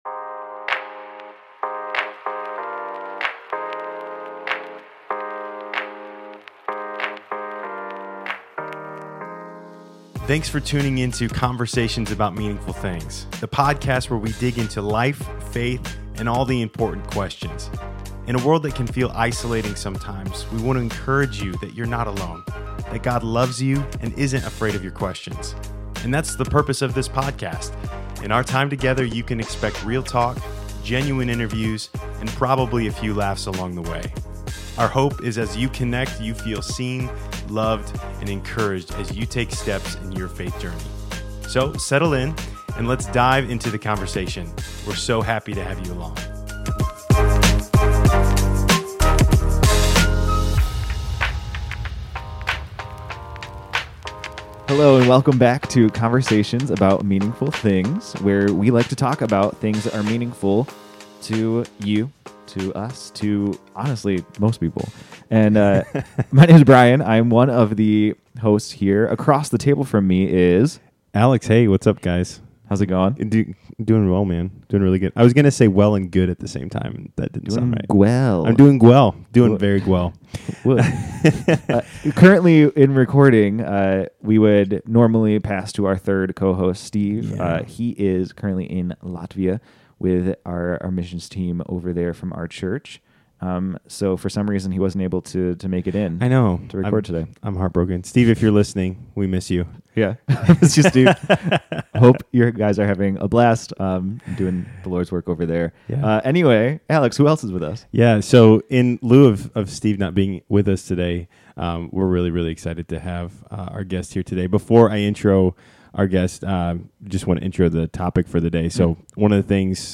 Tune in for an inspiring and thought-provoking discussion that will equip you with the tools to keep your faith strong and resilient, no matter what life throws your way.